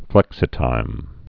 (flĕksĭ-tīm)